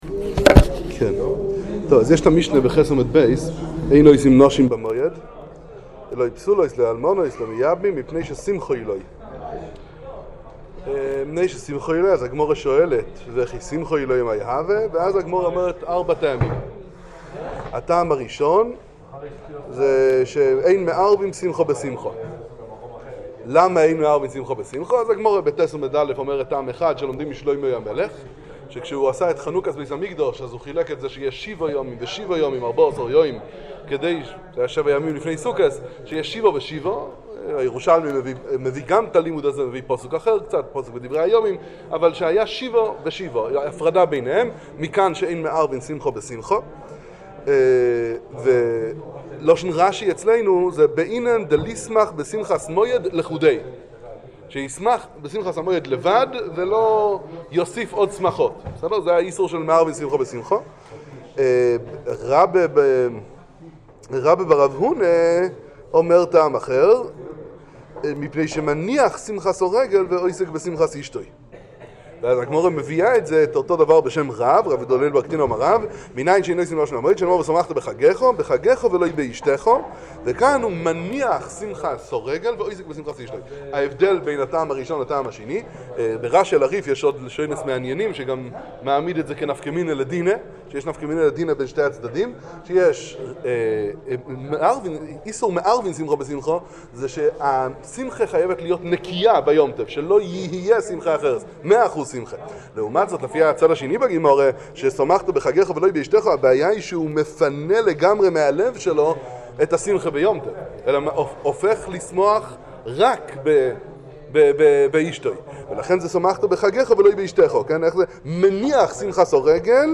שיעור בכולל